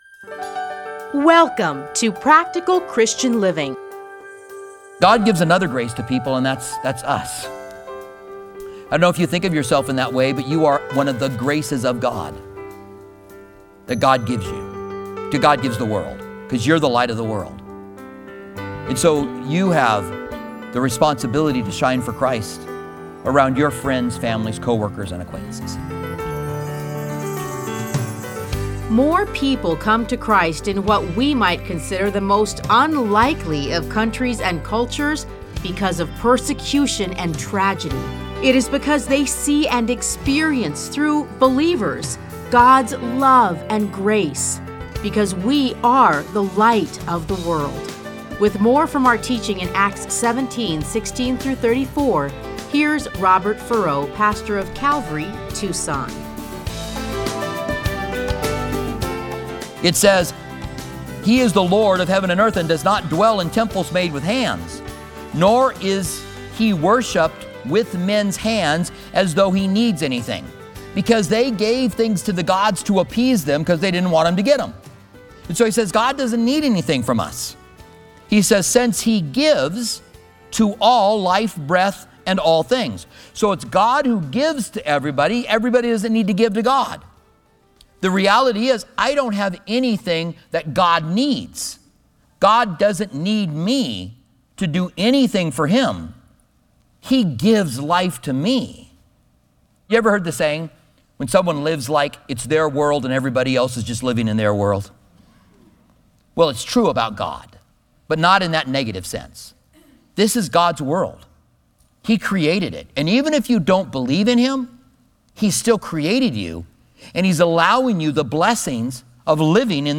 The Mars Hill Sermon - Part 2